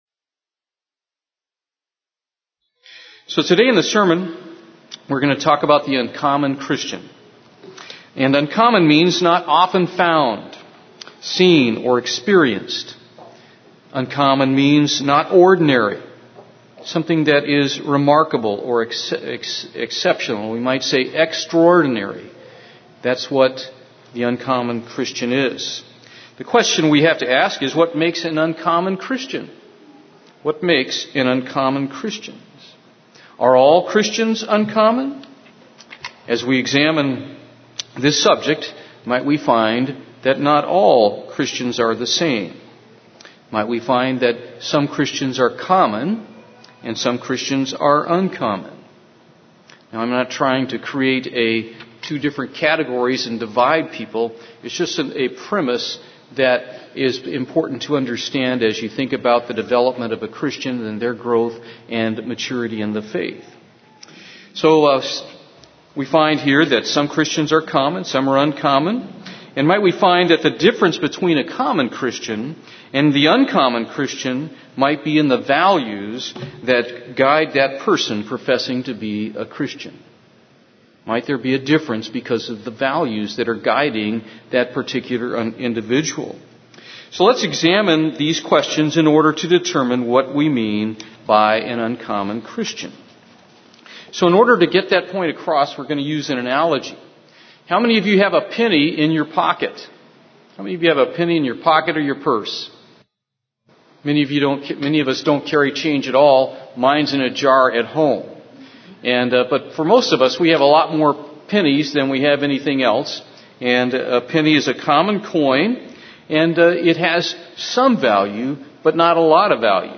Given in Houston, TX